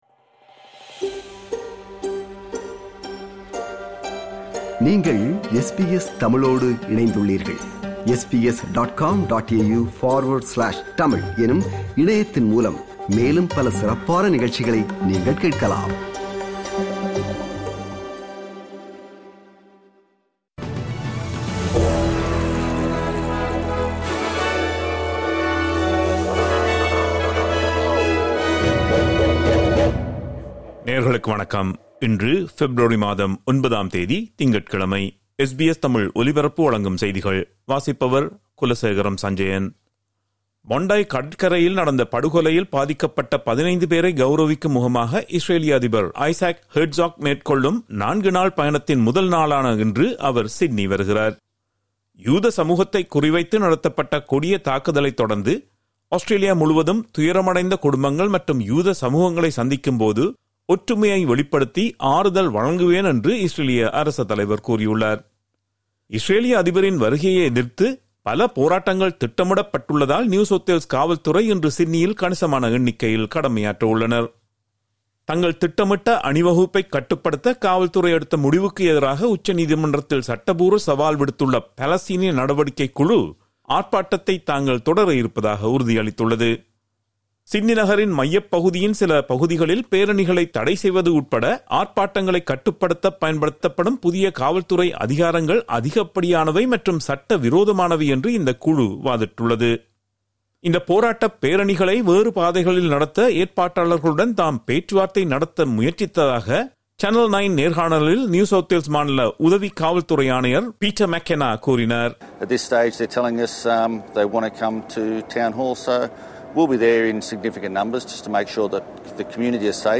இன்றைய செய்திகள்: 09 பெப்ரவரி 2026 - திங்கட்கிழமை
SBS தமிழ் ஒலிபரப்பின் இன்றைய (திங்கட்கிழமை 09/02/2026) செய்திகள்.